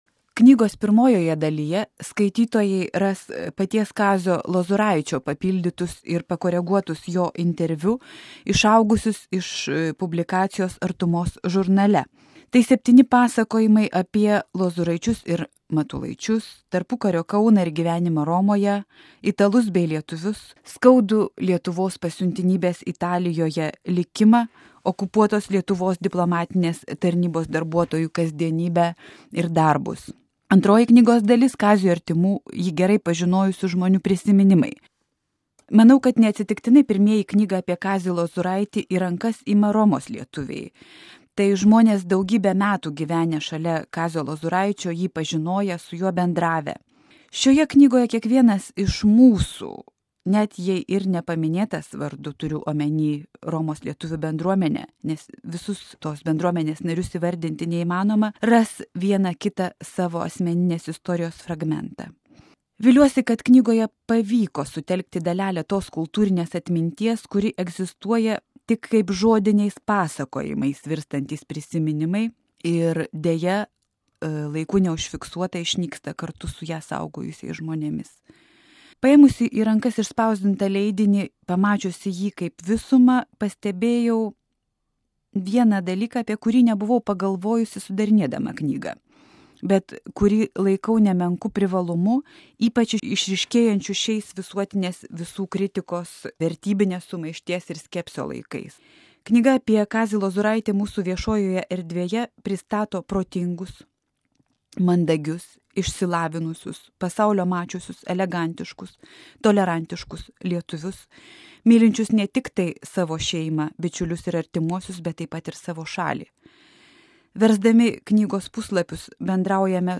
Apie ką tik išėjusią knygą prie mūsų mikrofono kalba jos sudarytoja